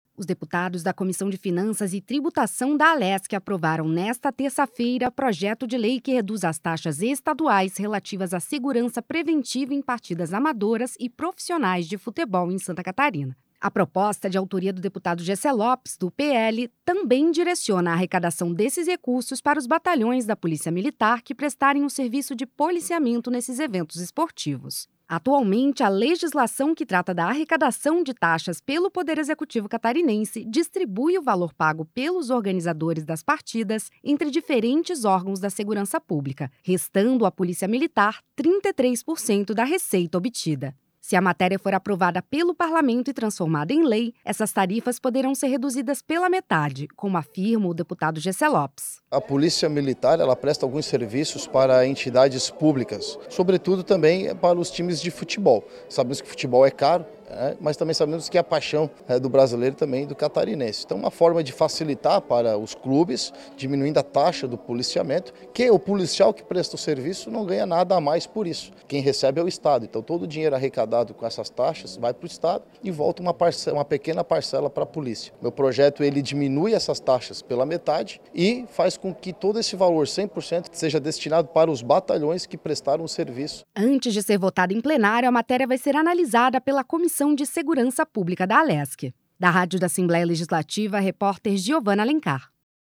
Entrevista com: